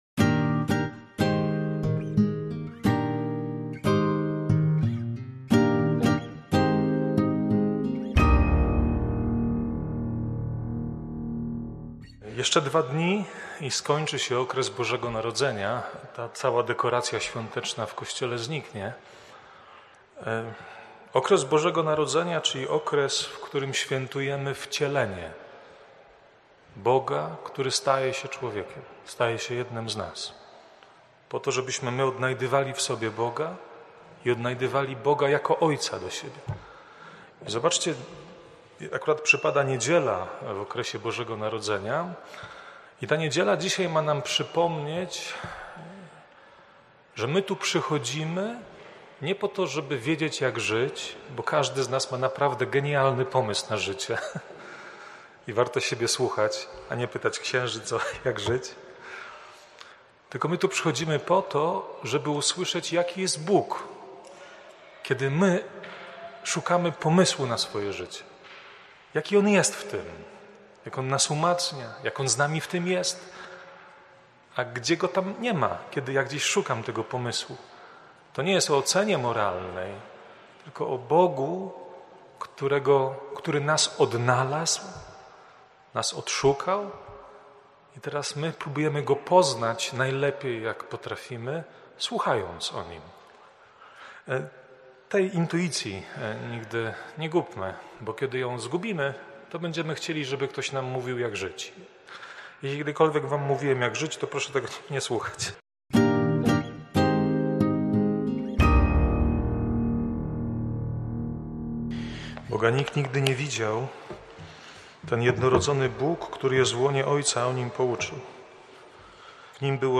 wprowadzenie do Liturgii, oraz homilia: